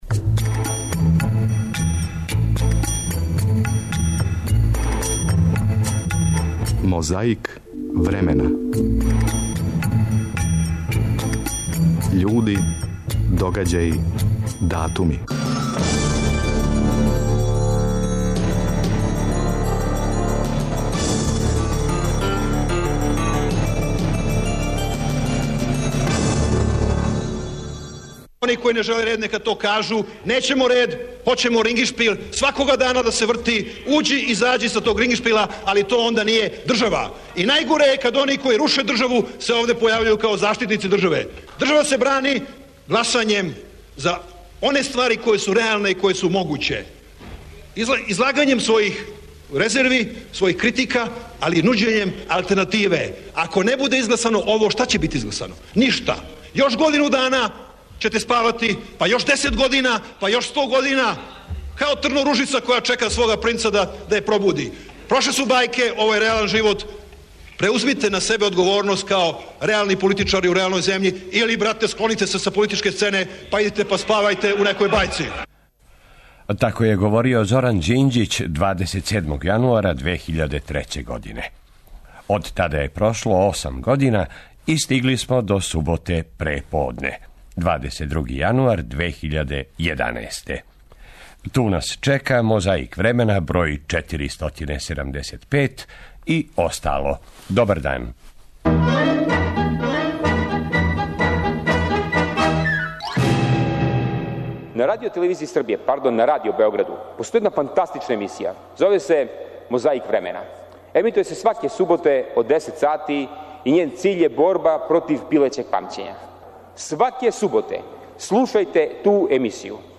Уз сећање да је 24. јануара 1887. рођена Жанка Стокић, чућете један стари снимак из тонског архива Радио Београда.